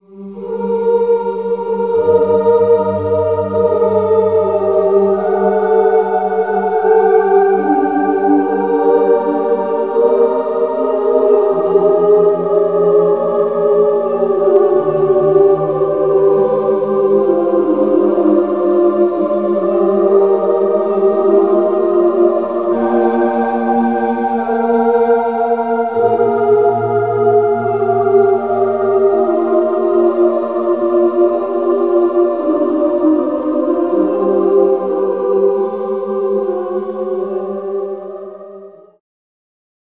Magnus Choir is a virtual instrument which can be used to create natural and synthetic choirs.
Oohs Choir
MC-37-Oohs-Choir-by-Magnus-Choir.mp3